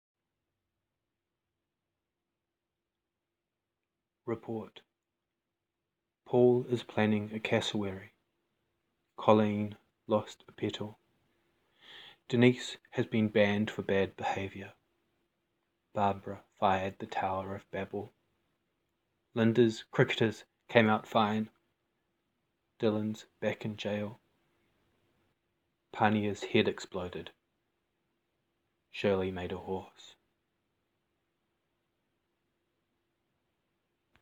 reads two poems